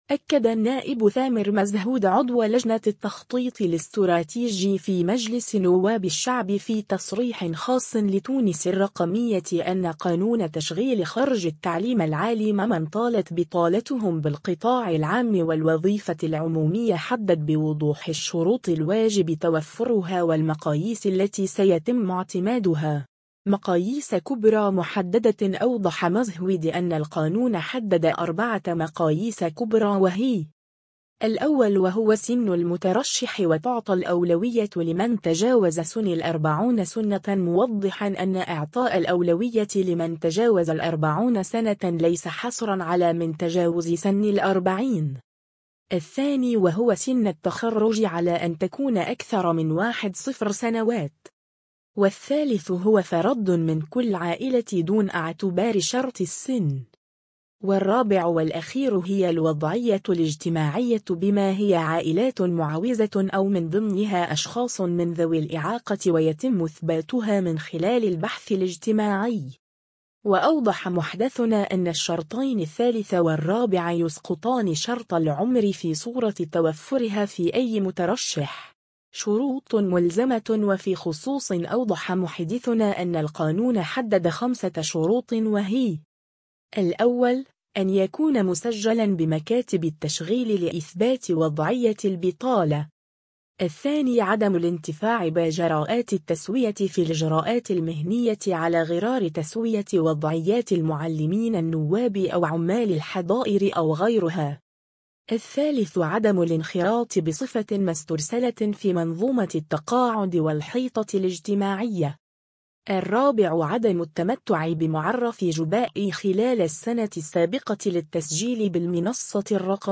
أكد النائب ثامر مزهود عضو لجنة التخطيط الاستراتيجي في مجلس نواب الشعب في تصريح خاص لـ «تونس الرقمية” أن قانون تشغيل خرجي التعليم العالي ممن طالت بطالتهم بالقطاع العام والوظيفة العمومية حدد بوضوح الشروط الواجب توفرها والمقاييس التي سيتم اعتمادها.